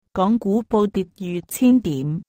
gong gu bou dit yu chin dim